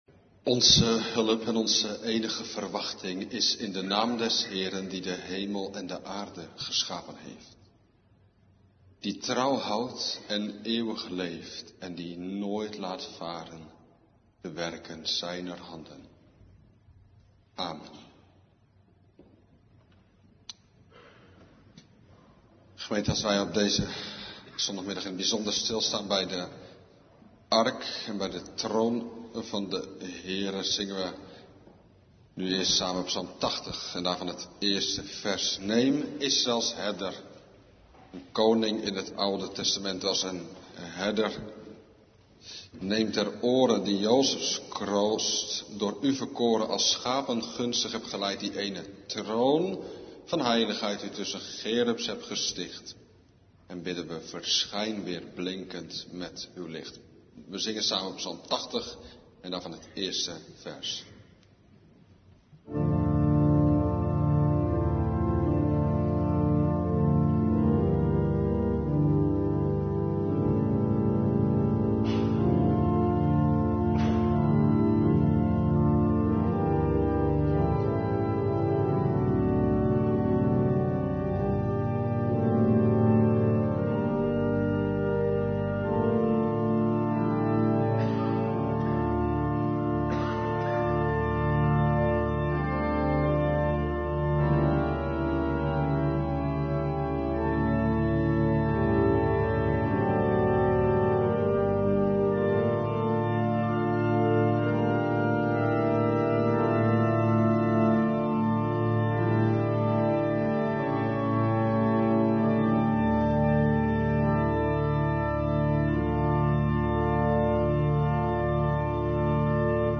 Psalm 80:1 Psalm 2:2 (na de geloofsbelijdenis) Psalm 132:5 en 7 Psalm 2:4 Psalm 47:4